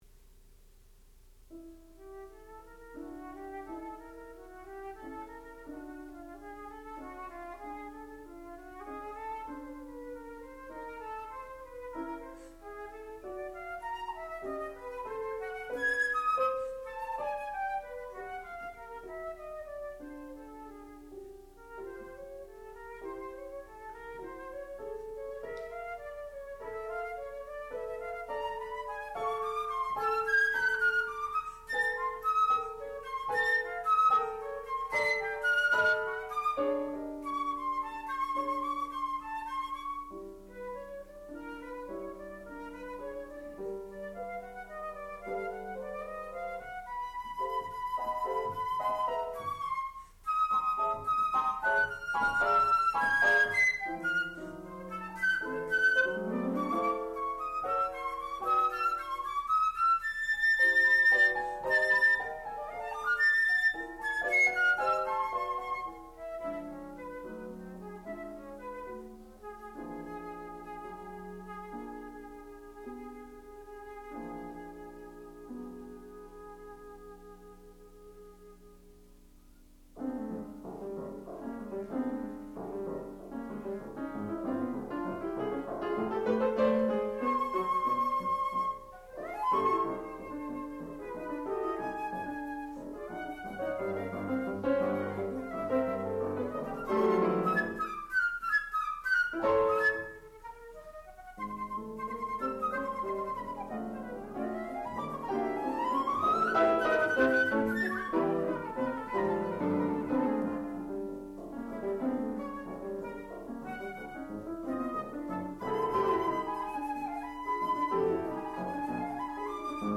sound recording-musical
classical music
Master's Recital